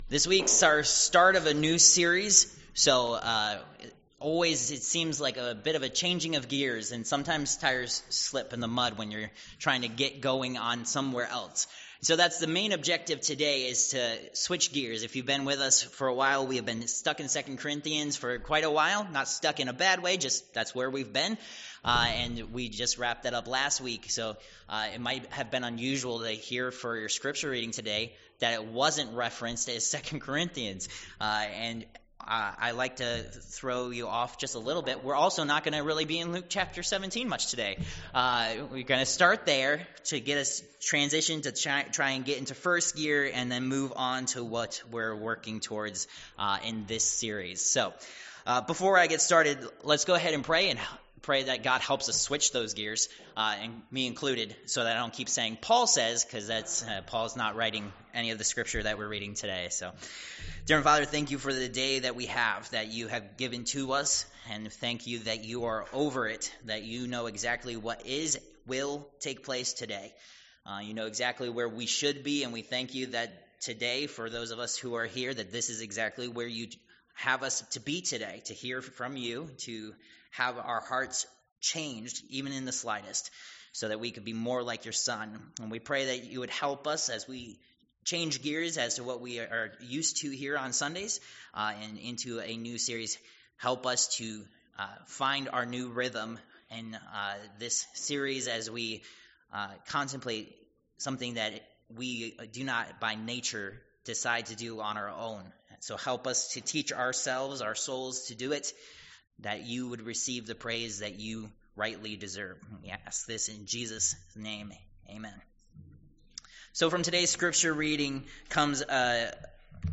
Service Type: Worship Service Topics: Thanksgiving